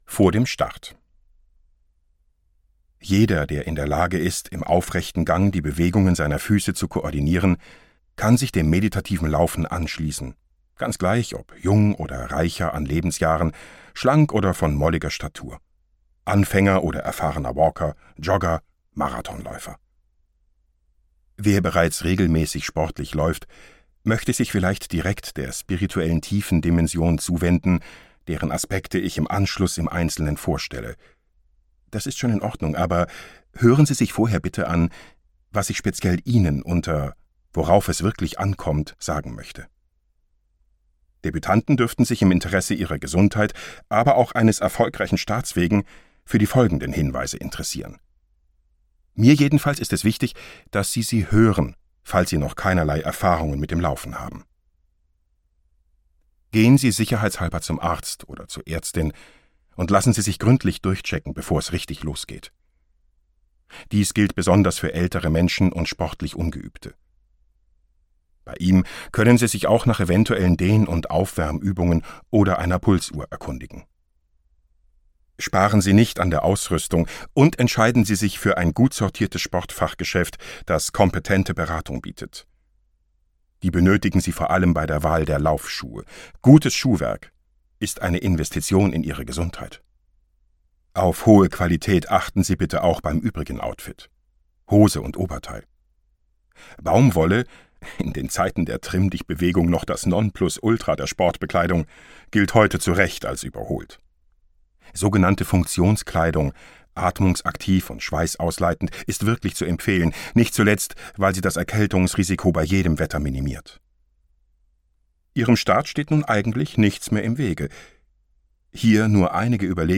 Hörbuch Die Seele läuft mit - Die meditative Laufschule für Fitness und innere Harmonie (Gekürzt)